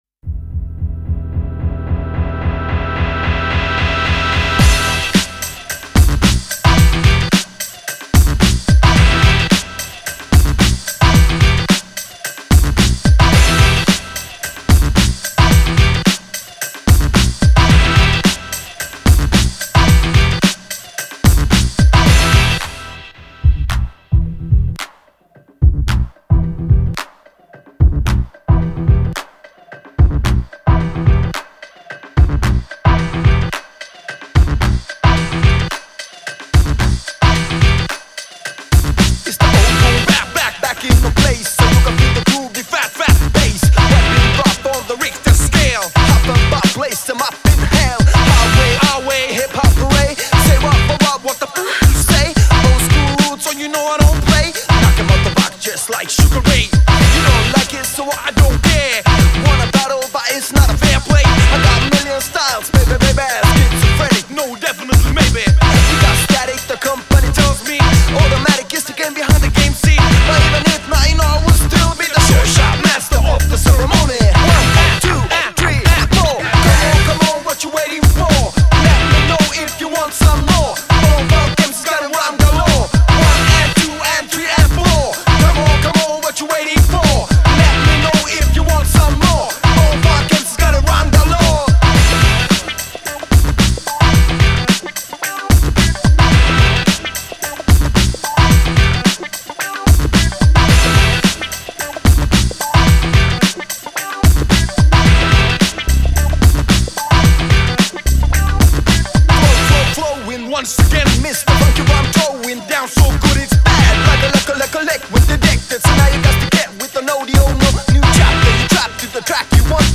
Жанр: хип-хоп-реп